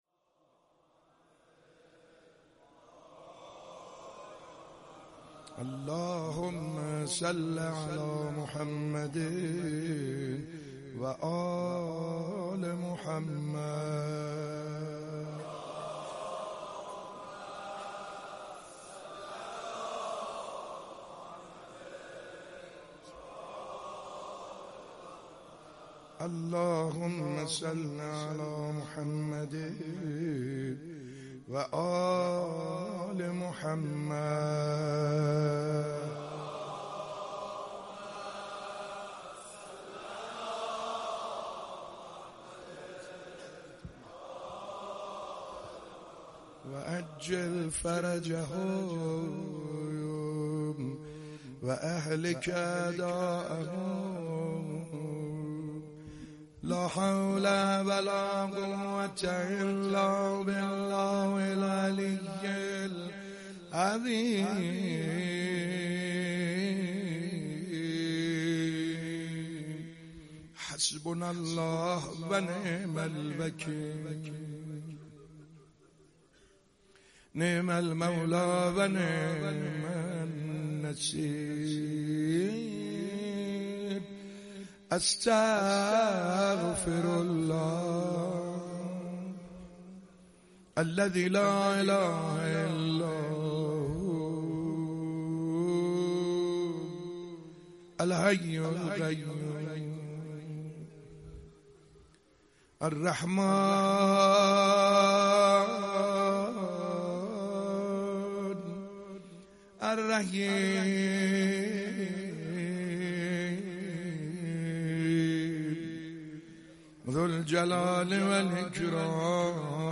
روز هشتم از مـــراســم عـــزاداری دهــه اول مـــحــرم الـحــرام در مهدیه امام حسن مجتبی(ع)